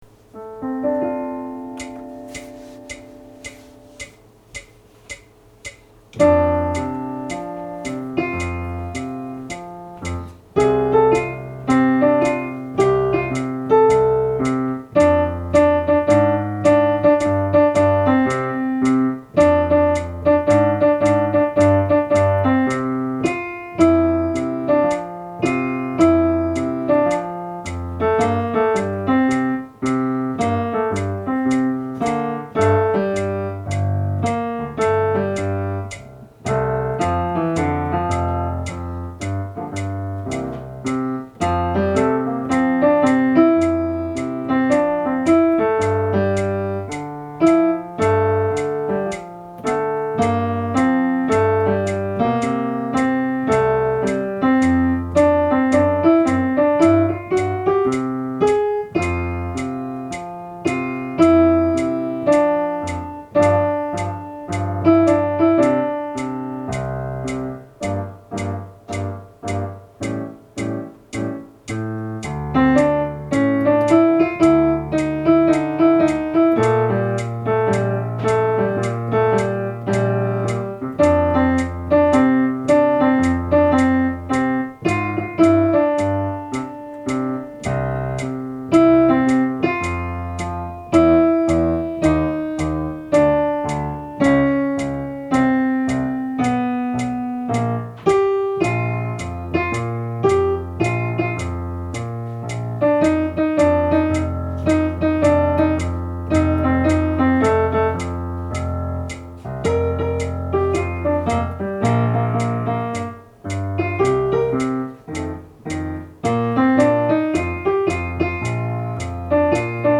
コーラスのオーディオファイル
４声
テンポを練習用に落としています。